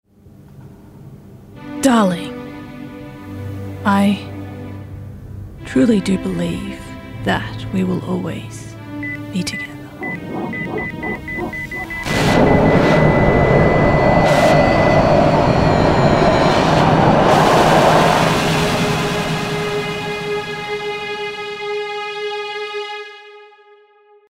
Lovers in Space - A Radio Drama
This drama has been created to demonstrate the use of atmos, sound effects and music in a podcast.